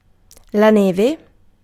Ääntäminen
Synonyymit cocaïne Ääntäminen France: IPA: [la nɛːʒ] Tuntematon aksentti: IPA: /nɛʒ/ Haettu sana löytyi näillä lähdekielillä: ranska Käännös Ääninäyte Substantiivit 1. neve {f} Suku: f .